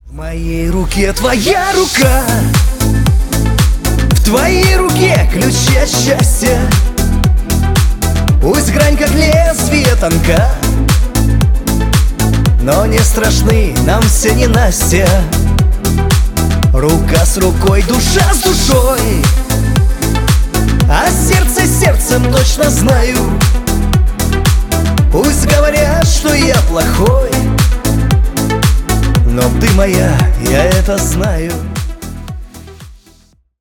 бесплатный рингтон в виде самого яркого фрагмента из песни
Шансон